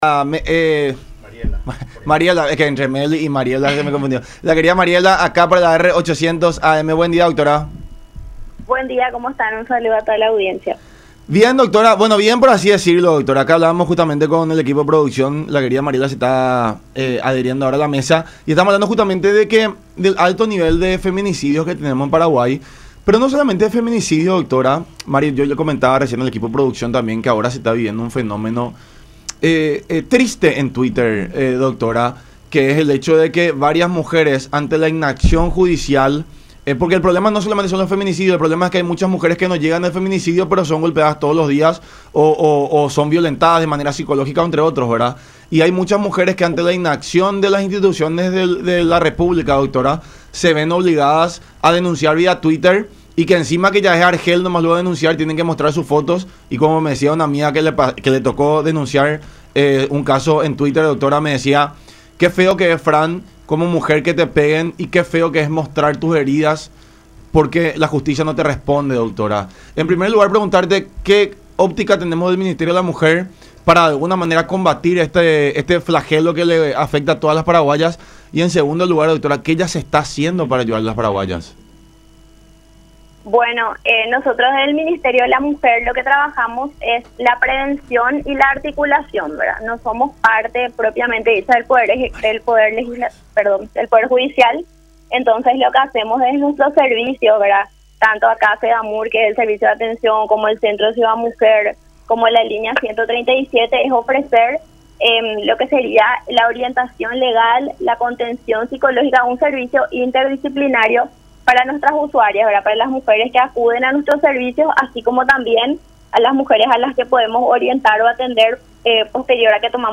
en conversación con La Unión Hace La Fuerza por Unión TV y radio La Unión